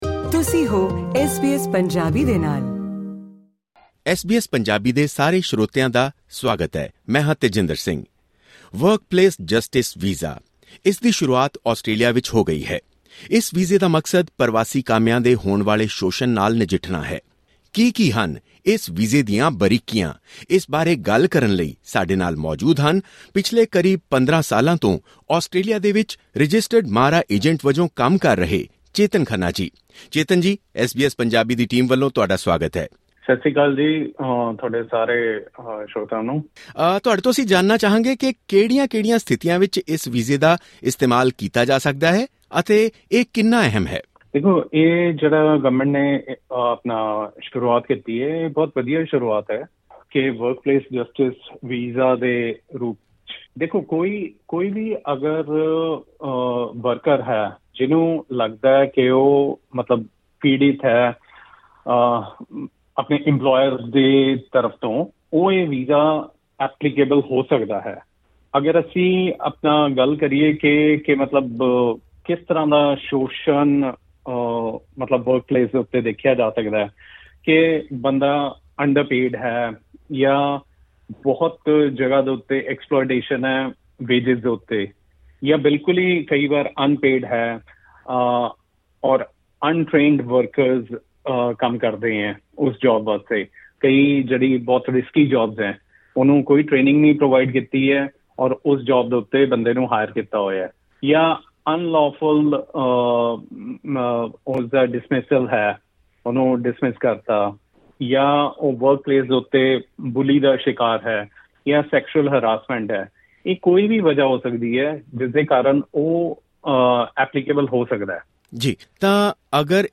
ਐਸ ਬੀ ਐਸ ਪੰਜਾਬੀ ਨੇ ਗੱਲ ਕੀਤੀ ਹੈ ਰਜਿਸਟਰਡ ਮਾਰਾ ਏਜੰਟ